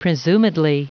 Prononciation du mot presumedly en anglais (fichier audio)
Prononciation du mot : presumedly